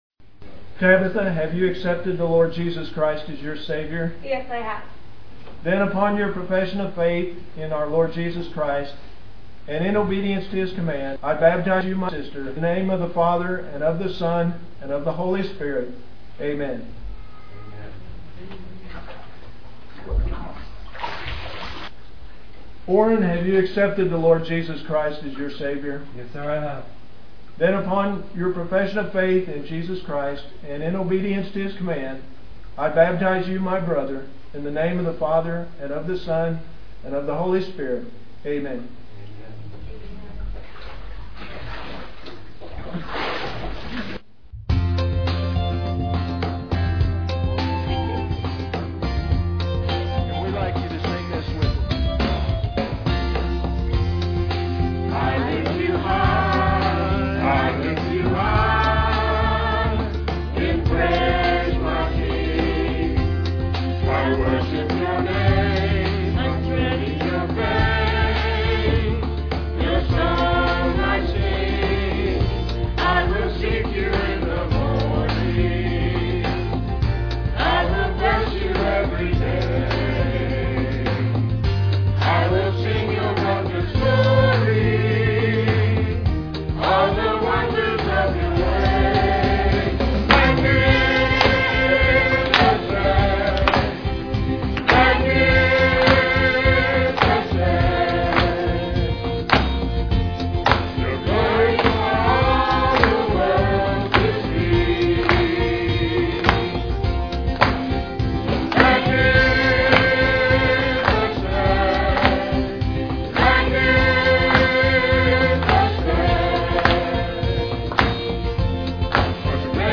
PLAY Joshua: Valiant in Fight, Part 1, Jan 7, 2007 Scripture: Joshua 1:1-9. Scripture reading
Solo